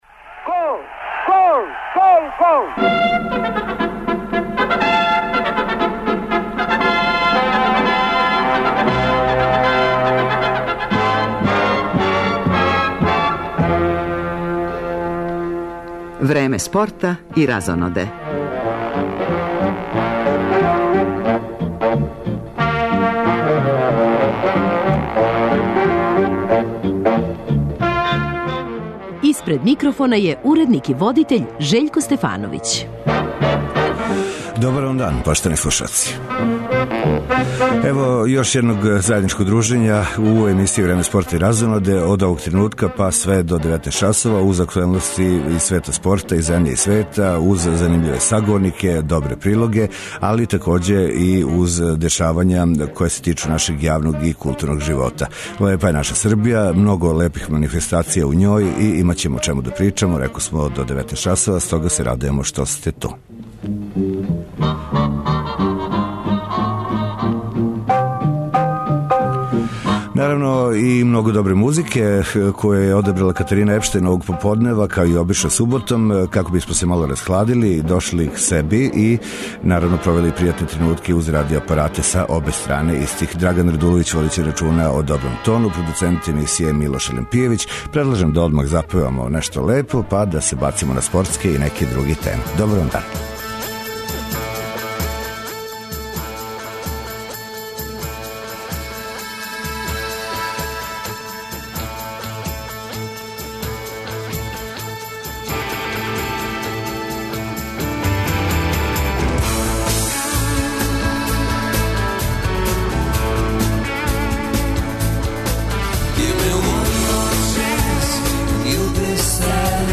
Породични магазин Радио Београда прати све актуелности из света спорта. Највише пажње привлачи Европско првенство у фудбалу, на коме почињу одлучујући мечеви у групној фази.